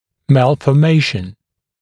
[ˌmælfɔː’meɪʃn][ˌмэлфо:’мэйшн]порок развития, мальформация, врожденный порок